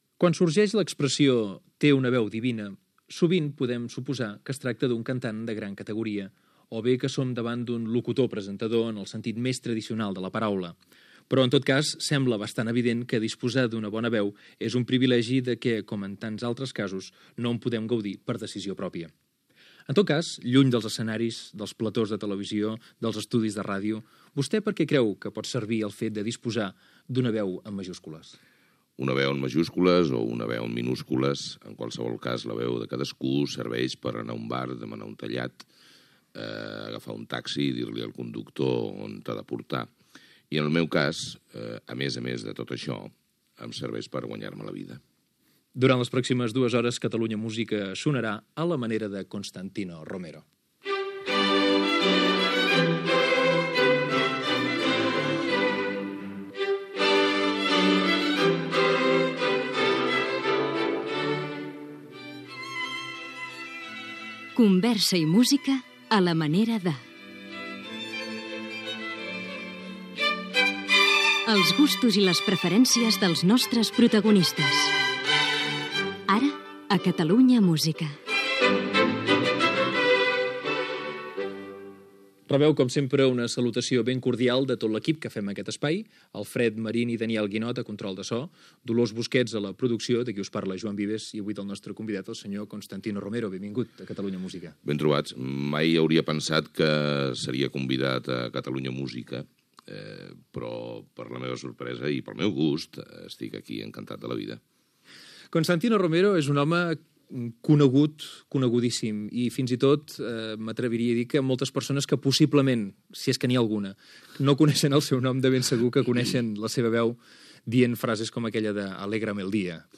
Pregunta inicial, careta del programa, equip, entrevista al presentador Constantino Romero
Entreteniment